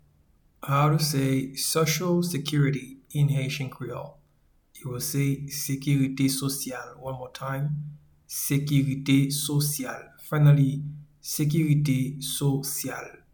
Pronunciation and Transcript:
Social-security-in-Haitian-Creole-Sekirite-sosyal-.mp3